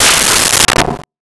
Metal Strike 02
Metal Strike 02.wav